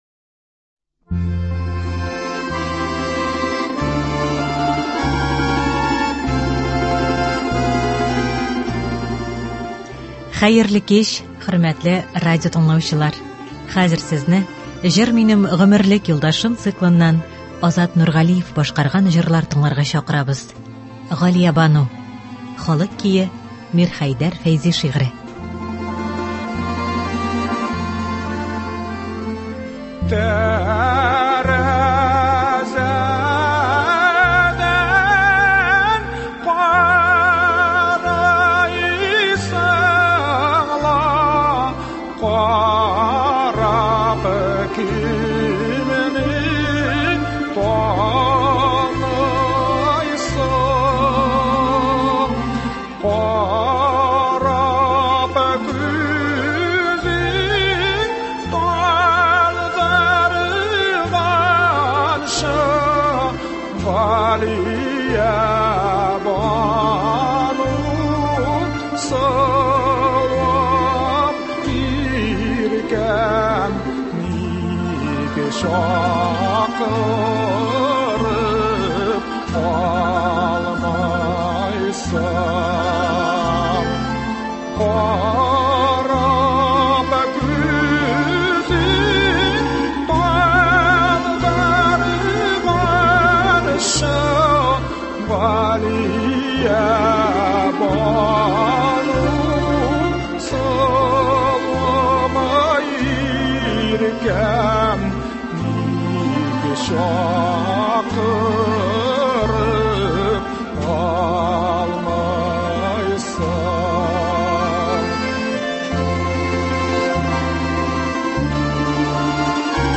Үзешчән башкаручылар чыгышы.
Концерт (12.02.24)